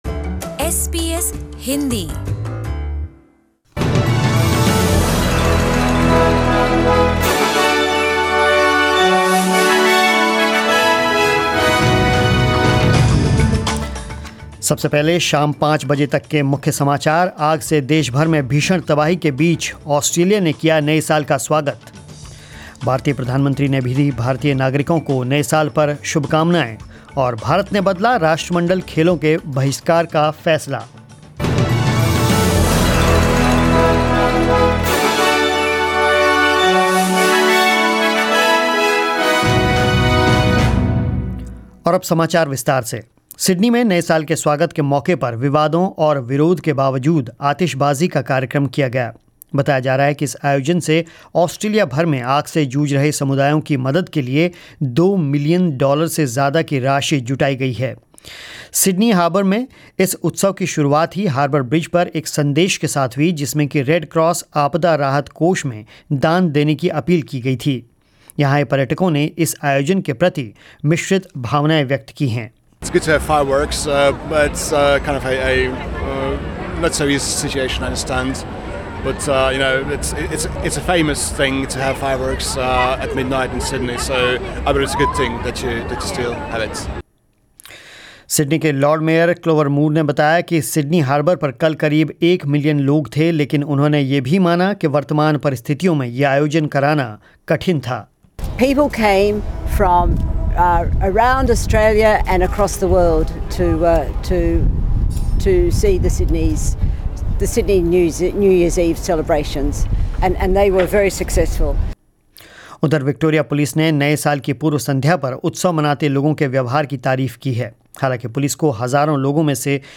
News in Hindi